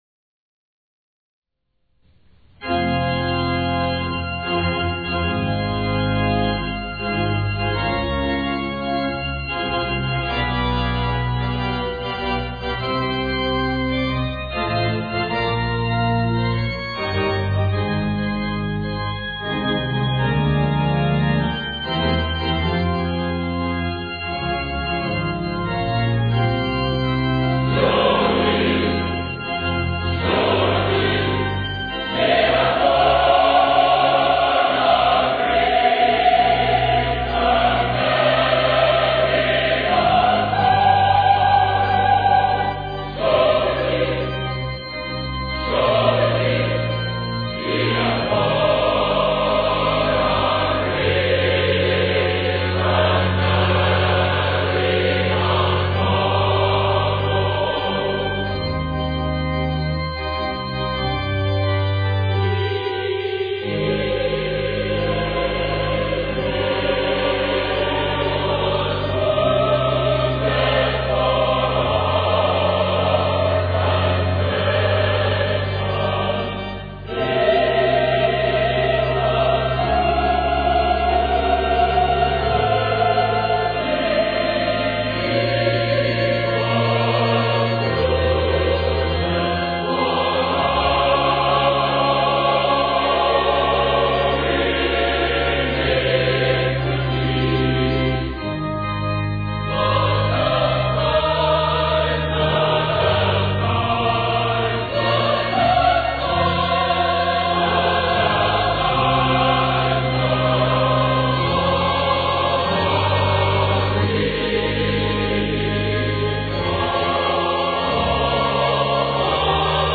THE CHANCEL CHOIR